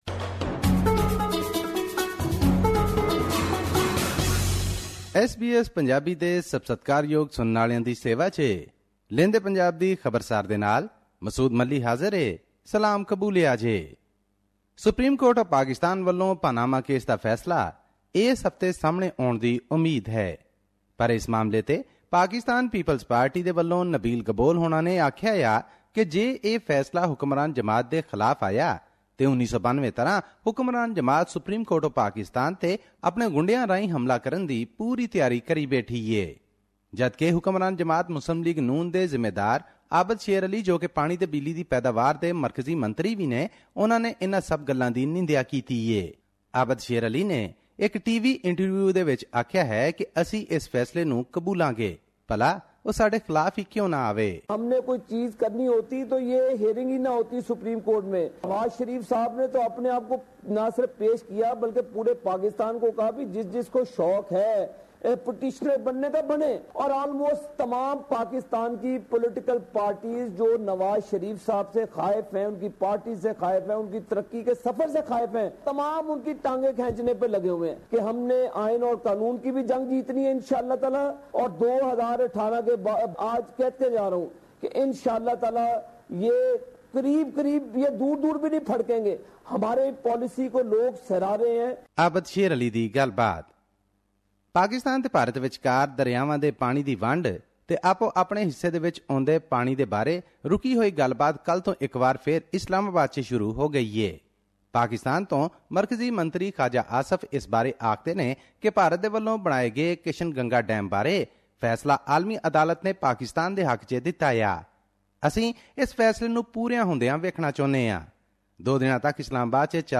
Here's the podcast in case you missed hearing it on the radio.